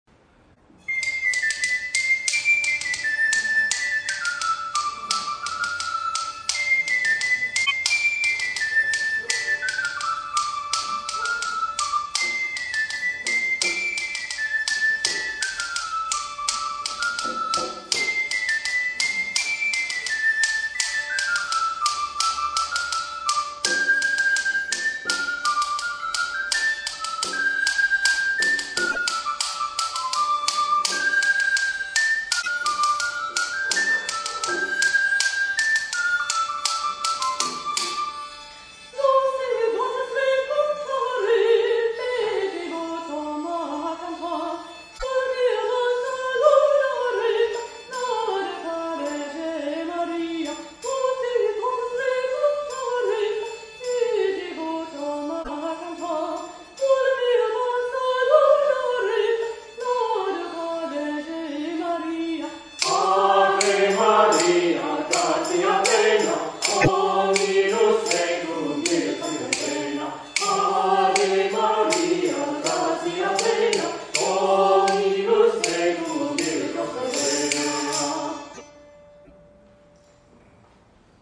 Click to download MP3 recordings of past concerts.